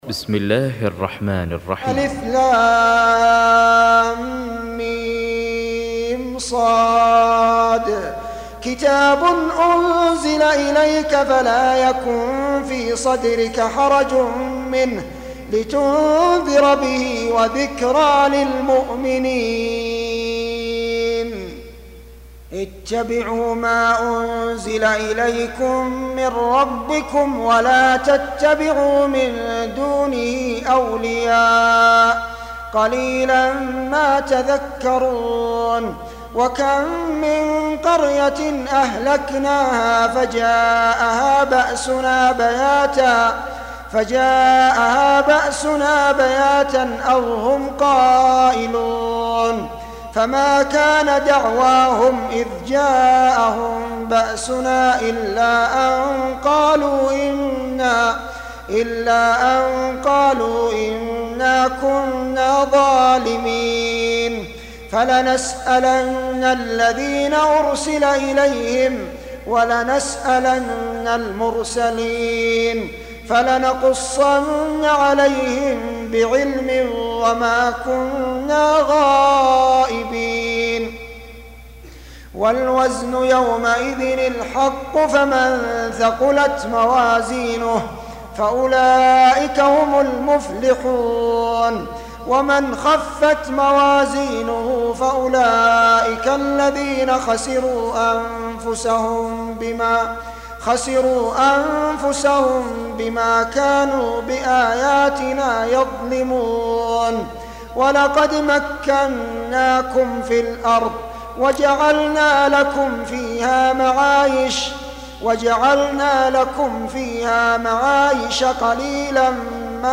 Surah Repeating تكرار السورة Download Surah حمّل السورة Reciting Murattalah Audio for 7. Surah Al-A'r�f سورة الأعراف N.B *Surah Includes Al-Basmalah Reciters Sequents تتابع التلاوات Reciters Repeats تكرار التلاوات